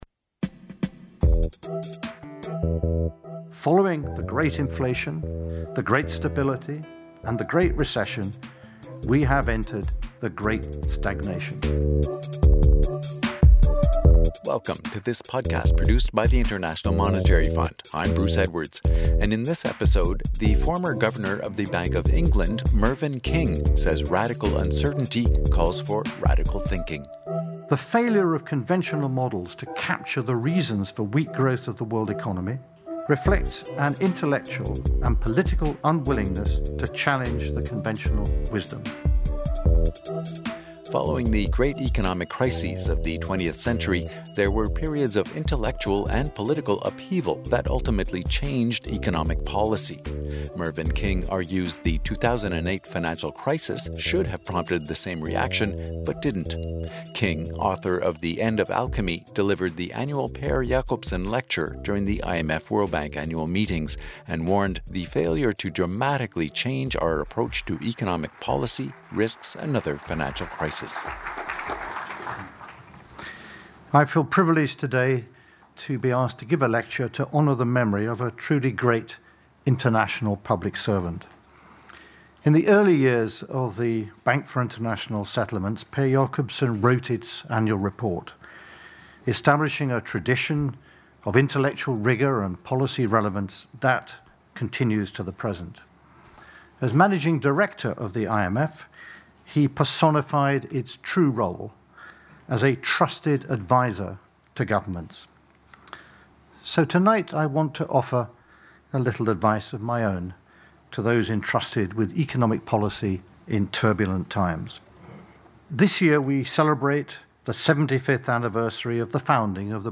Mervyn King, former Governor of the Bank of England, argues the 2008 financial crisis should have prompted the same reaction but didn’t. King delivered this year's Per Jacobsson Lecture during the IMF-World Bank Annual Meetings, and warned the failure to dramatically change our approach to economic policy risks another financial crisis.